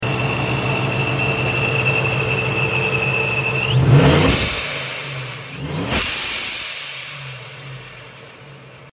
Whistlin hair dryer on a sound effects free download
Whistlin hair dryer on a single cab f one fiddy all wheel pull